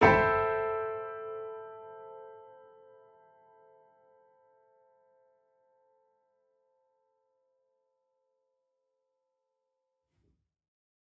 Index of /musicradar/gangster-sting-samples/Chord Hits/Piano
GS_PiChrd-Amin6+9.wav